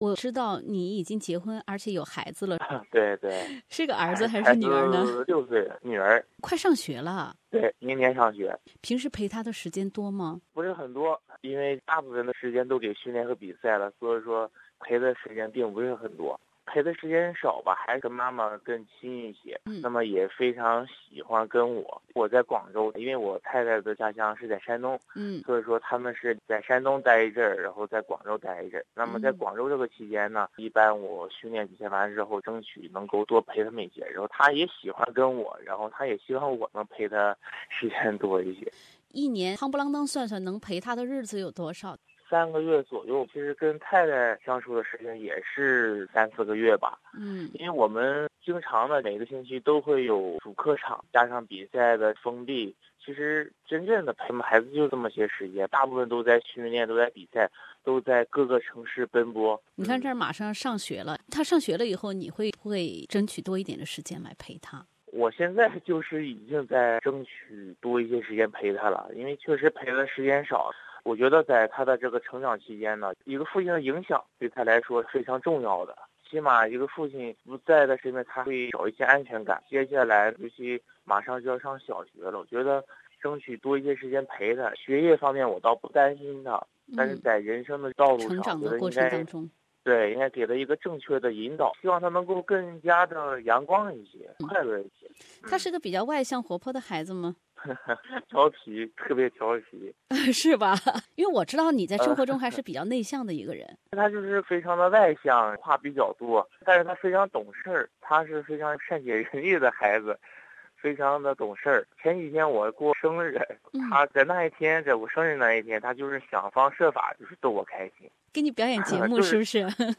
专访中国国家男子足球队队长 冯潇霆 02